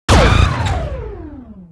1 channel
LaserBlueD.wav